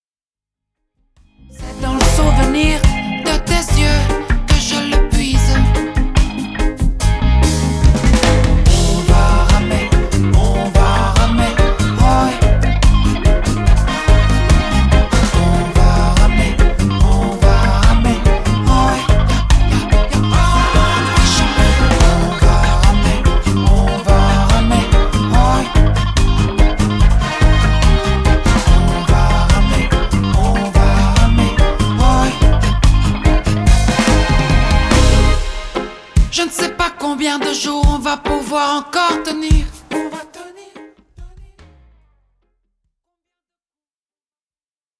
reggae
Enregistré dans un grand studio de Bruxelles